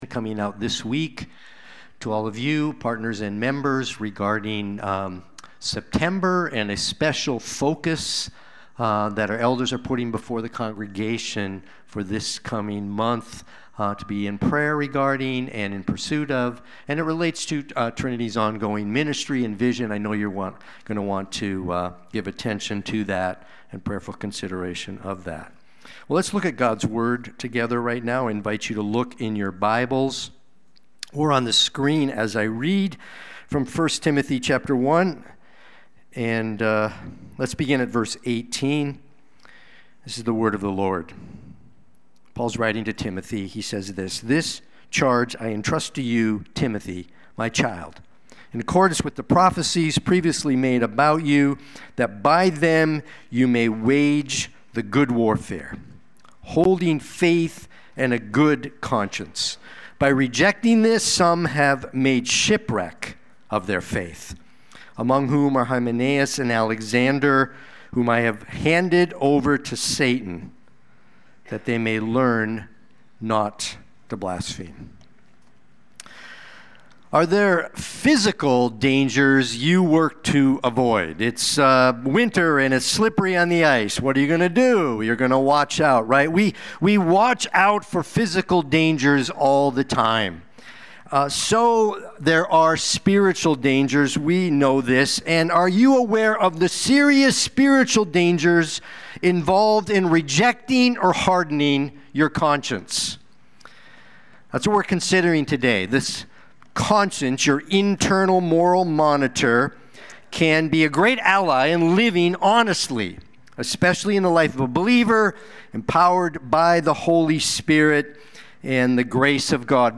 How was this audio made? Sunday-Worship-main-8-24-25.mp3